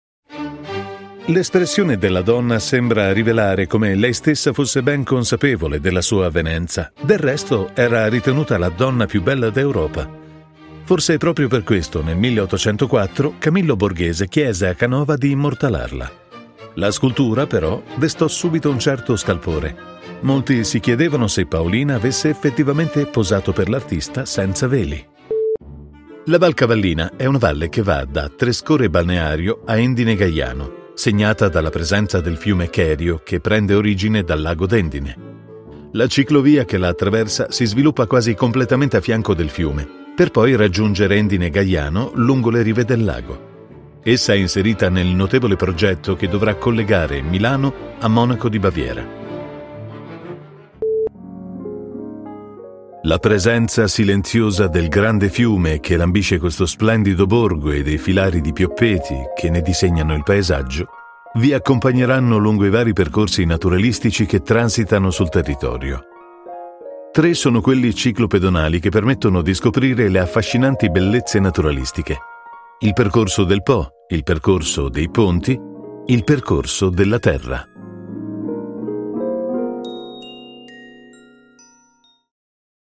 Weltweit bekannte Marken vertrauen ihm und er liefert professionelle Voiceover-Dienste mit Wärme, Klarheit und Schnelligkeit aus seinem hochmodernen Studio.
Audioguides
UAD Apollo X8, Mac Pro, U87Ai, TLM103, TLM67 und mehr.